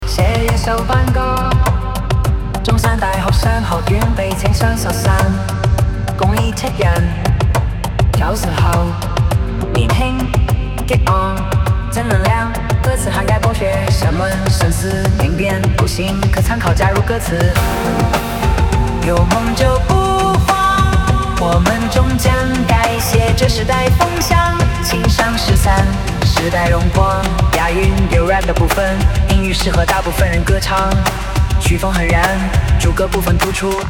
Prompt：写一首班歌，中山大学商学院——青商十三，共27人，90后，年轻，激昂，正能量，歌词涵盖“博学，审问，慎思，明辨，笃行”，可参考加入歌词：商海浮沉，有梦就不慌，我们终将改写这时代风向；青商十三，时代荣光。押韵，有rap的部份，音域适合大部分人歌唱。曲风很燃，主歌部分突出。